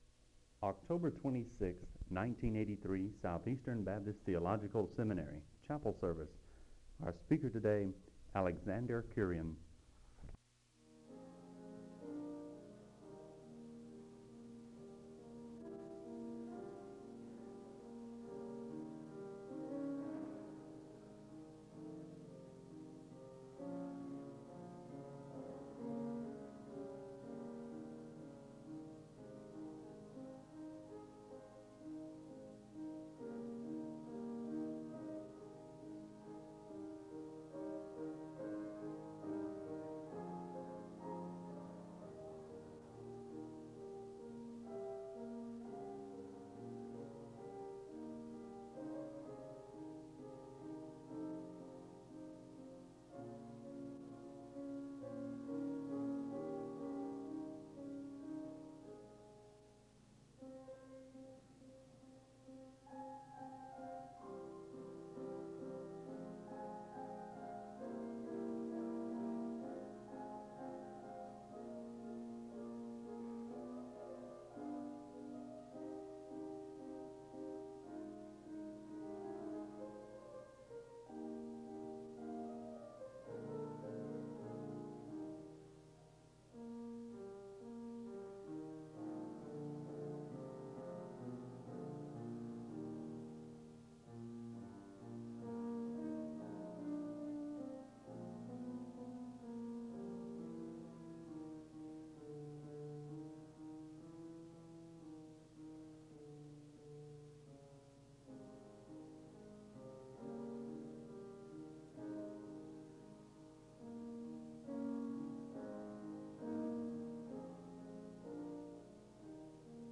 SEBTS Chapel
The service begins with piano music (00:00-04:57).
The audience is led in a song of worship (06:11-11:17). The speaker gives a word of prayer (11:18-11:59).
A student delivers a personal testimony of how he came to faith after living in an abusive home (13:37-21:39).
The service ends with a word of prayer (45:22-45:43).